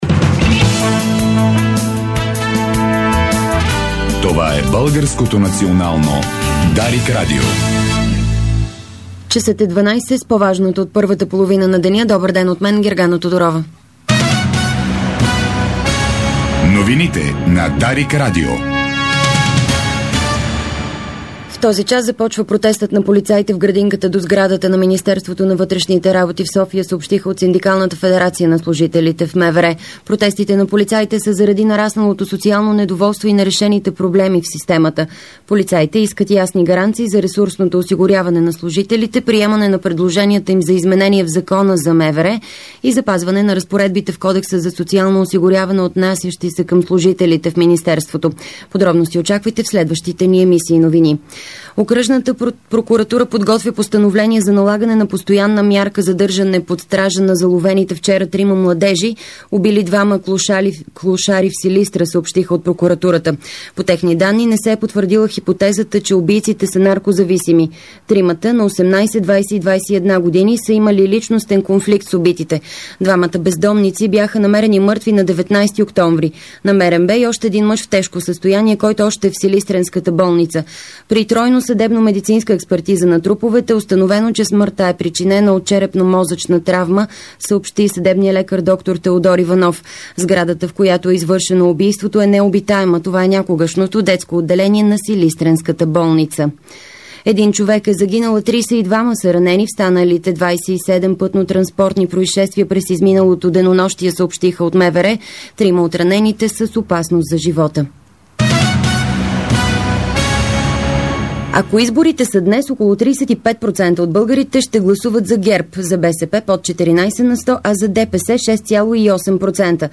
Обедна информационна емисия - 24.10.2010